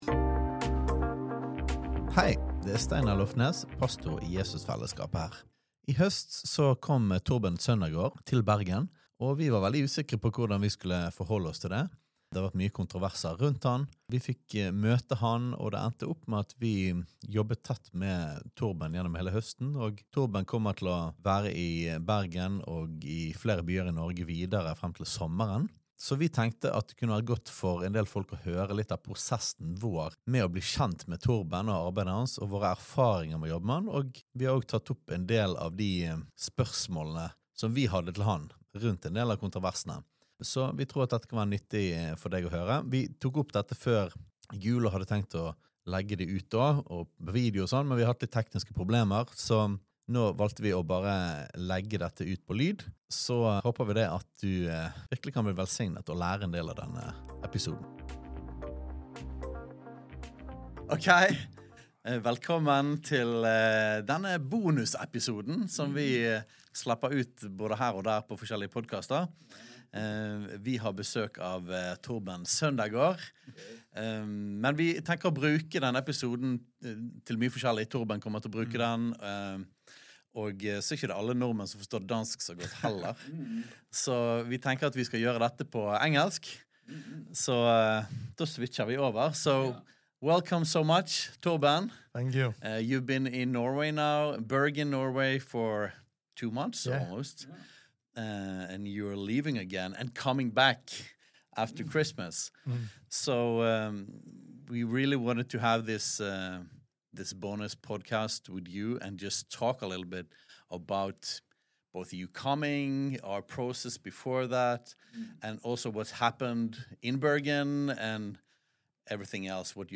Smakebit: En ærlig samtale